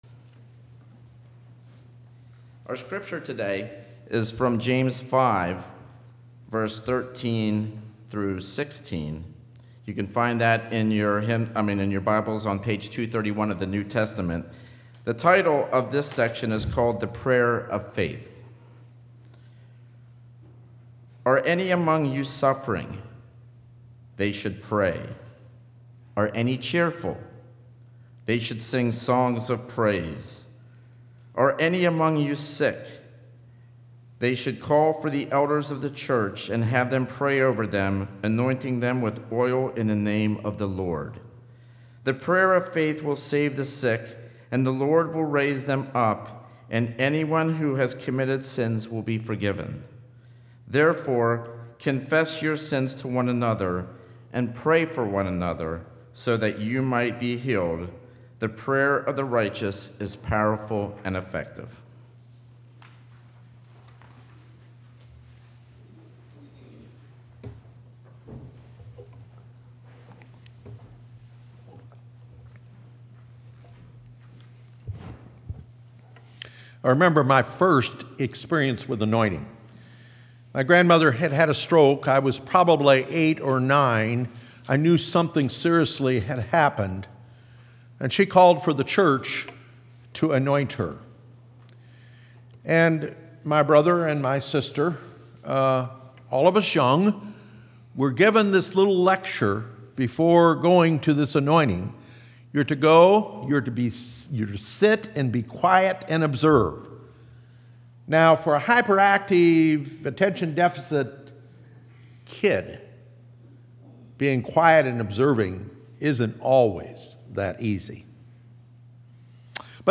Bible Text: James 5:13-16 | Preacher